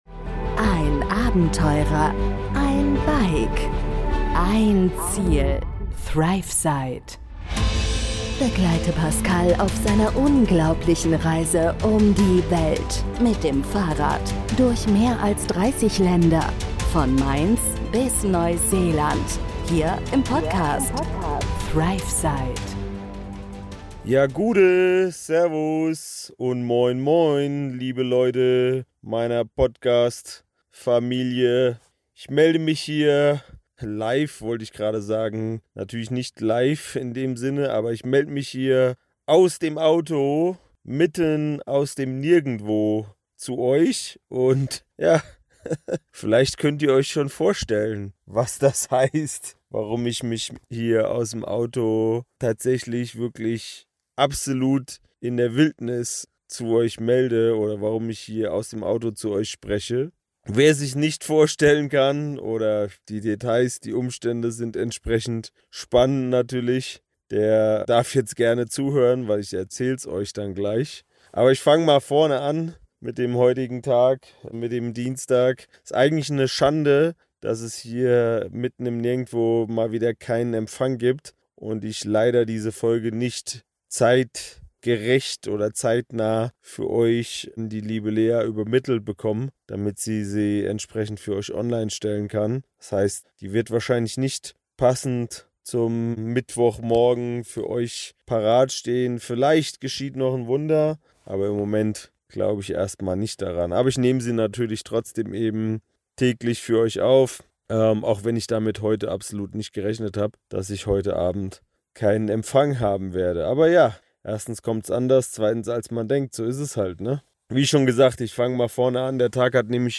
Ich melde mich heute aus dem Auto, mitten aus der Wildnis, und ich kann euch sagen: Es läuft nicht alles nach Plan... Warum ich hier gestrandet bin und was uns auf dieser chaotischen Reise passiert ist, erfahrt ihr in dieser Folge.